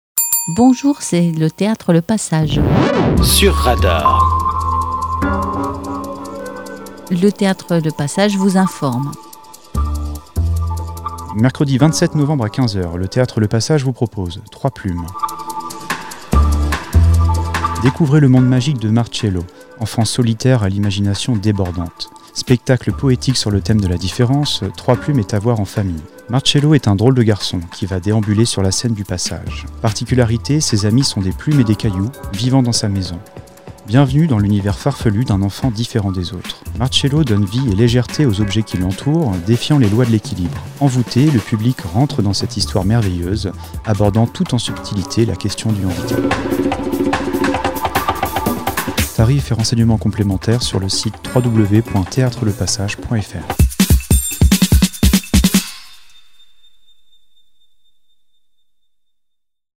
Régulièrement, différentes associations Fécampoises viennent dans nos studios pour enregistrer leurs différentes annonces pour vous informer de leurs activités